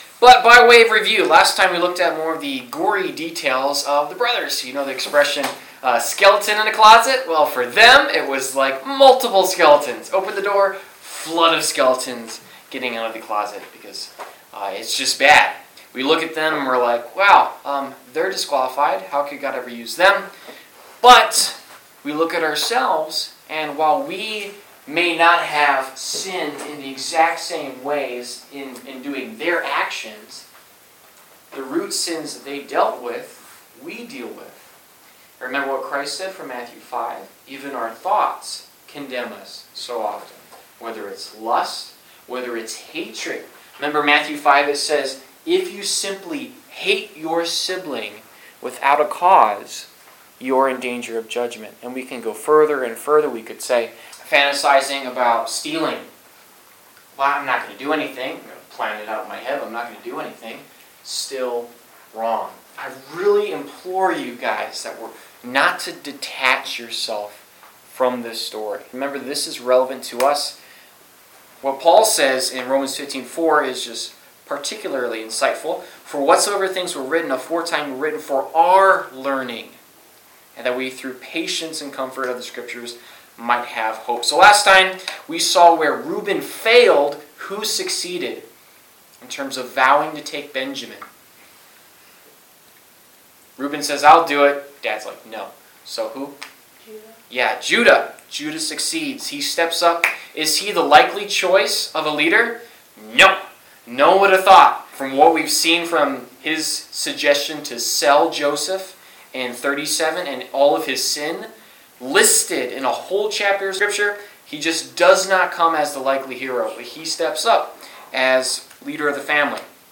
Service Type: Wednesday Night - Youth Group Topics: Forgiveness , God's Sovereignty , Loyalty , Reconciliation , Reunion , Testing